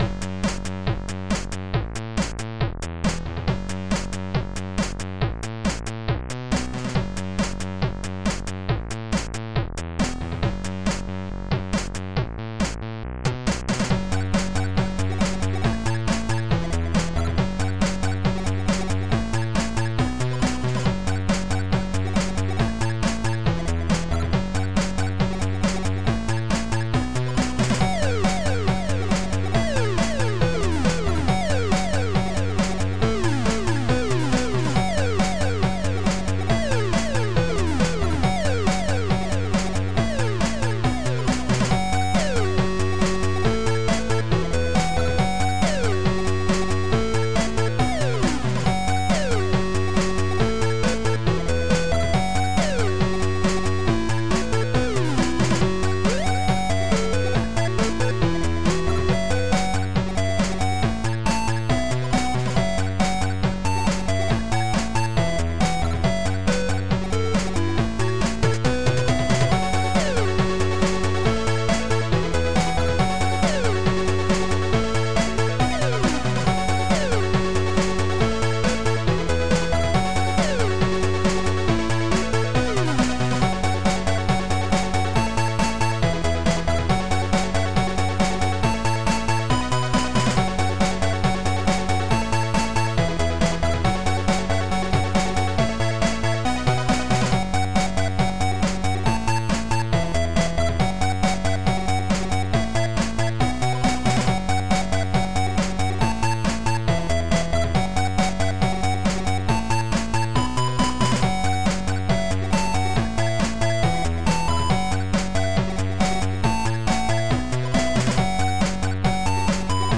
redneck 8 bit R2R DAC edition
>audio from the original file, but its basically the same except some power supply noise from the $3 power bank and it being 8 bit